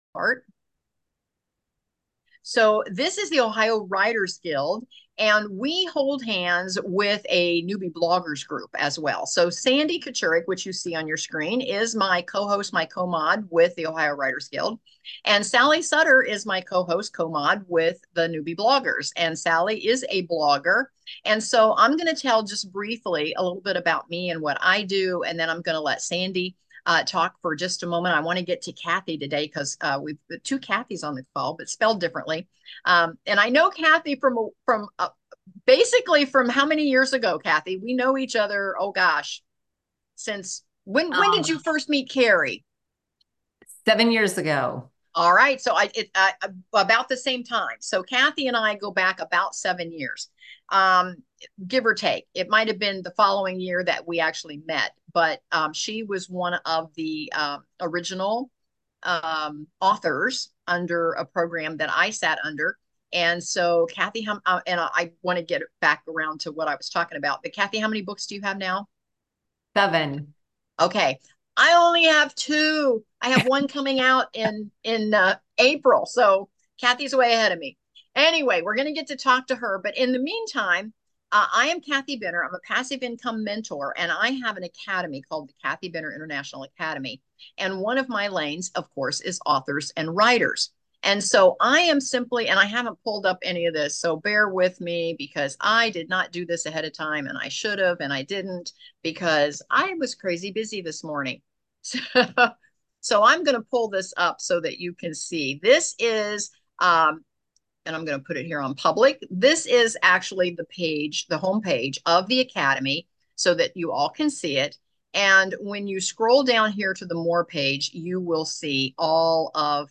Ohio Writers' Guild | Interview
📚 Embark on an exciting literary journey with our engaging monthly virtual meetup on Zoom 🌐✨, tailored for writers at all levels!